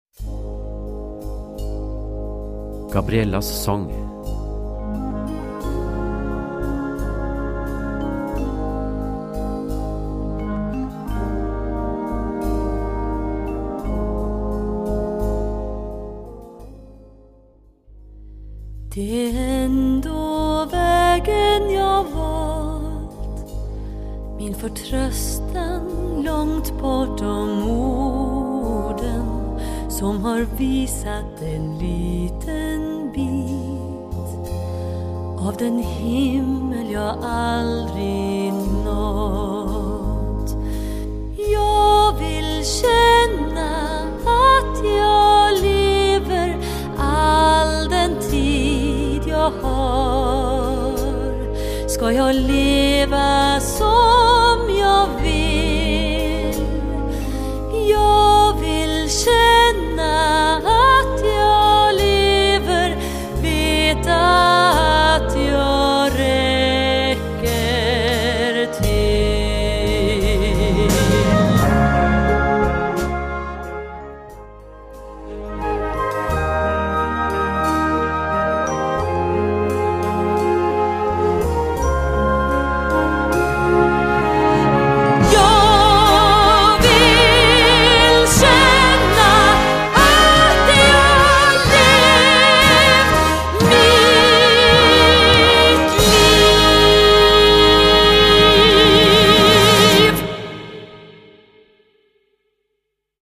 Gattung: Solo für diverse Instrumente oder Gesang
Besetzung: Blasorchester
Solo für Gesang oder diverse Instrument und Blasorchester.